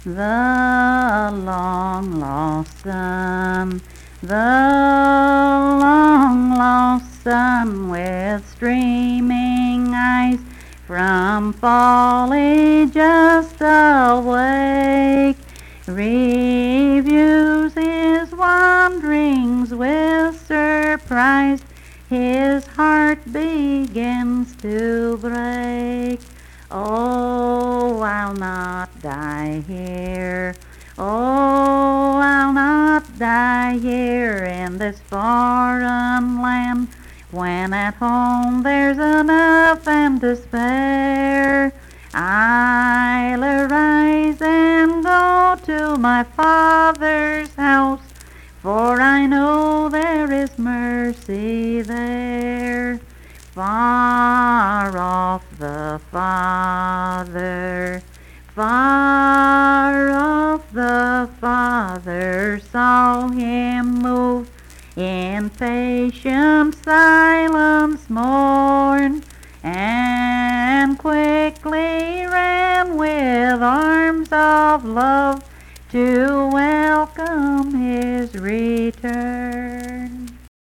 Unaccompanied vocal music performance
Verse-refrain 3(5).
Voice (sung)